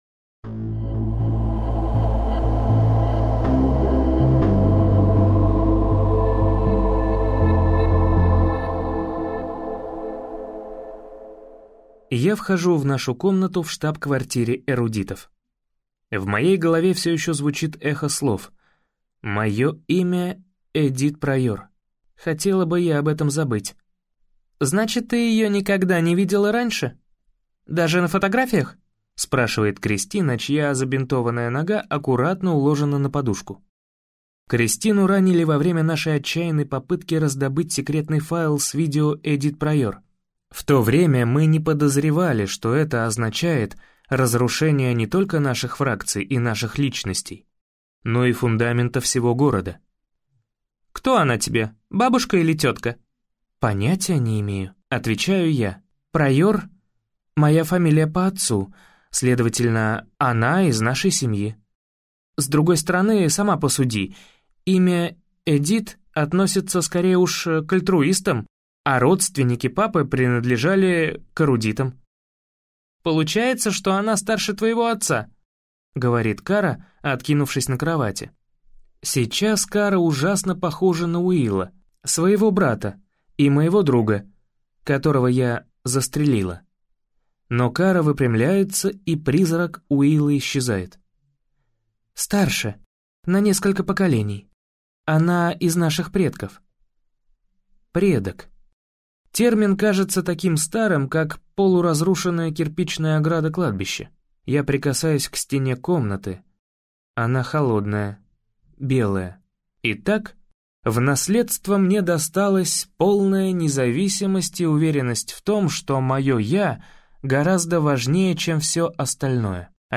Аудиокнига Эллигент - купить, скачать и слушать онлайн | КнигоПоиск